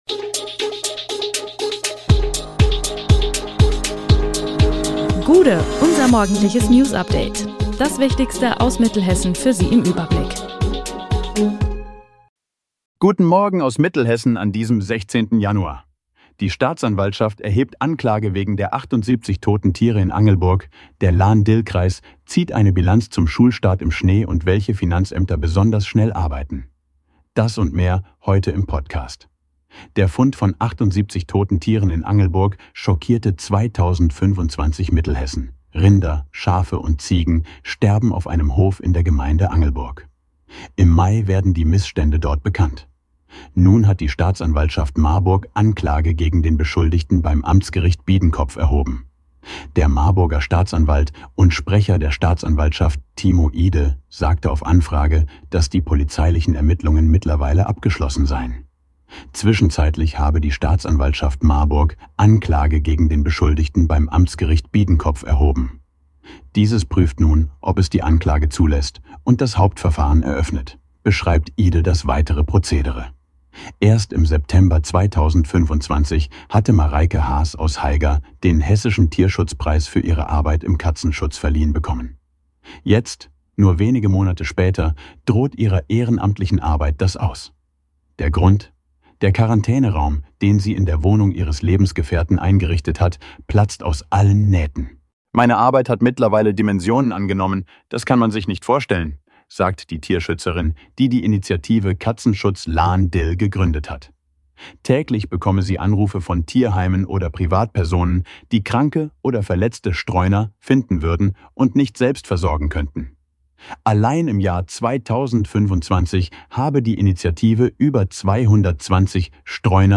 Der Podcast am Morgen für die Region!
Nachrichten